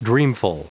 Prononciation du mot dreamful en anglais (fichier audio)
Prononciation du mot : dreamful